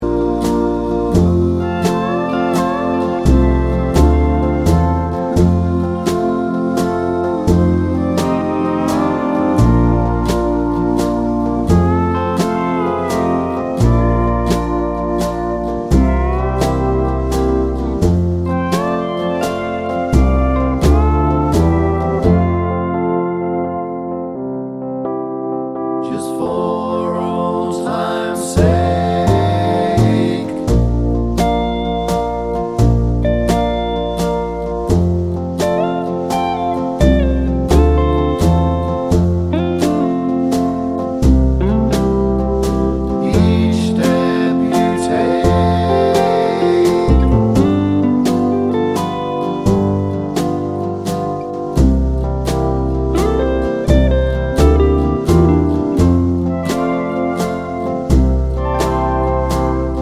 no Backing Vocals Country (Female) 2:26 Buy £1.50